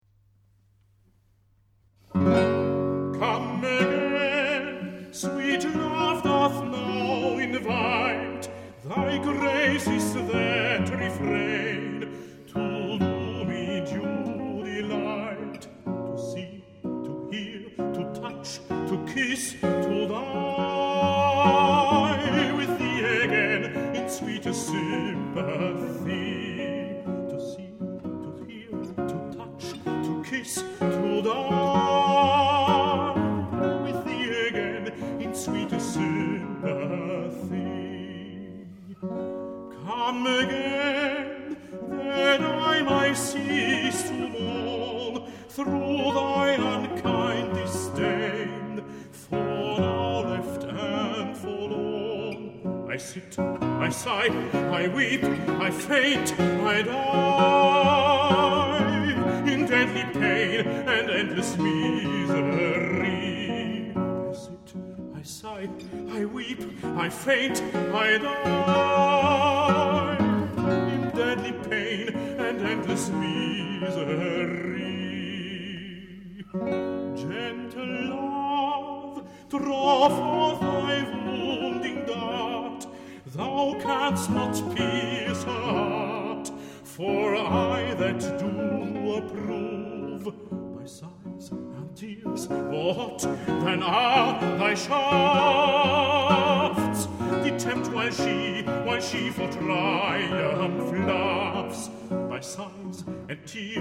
The very best from 16 concerts
guitar
tenor